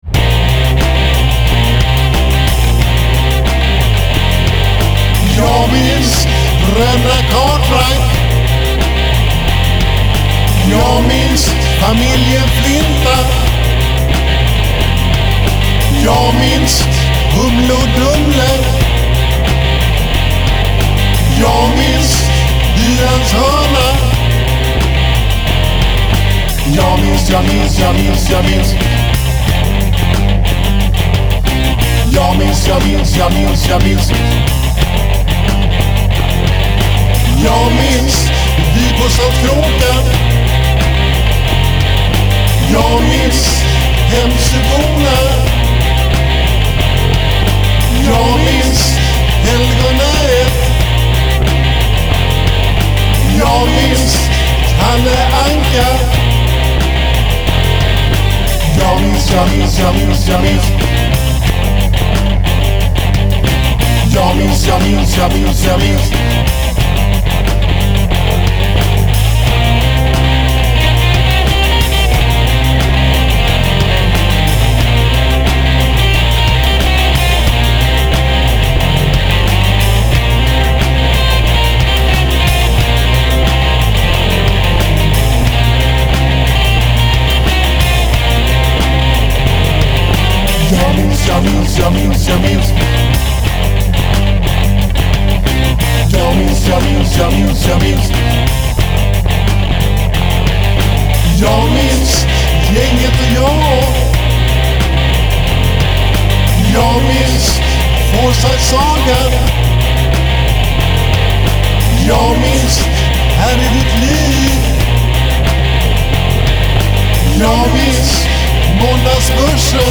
Bb C Bb C Bb C F G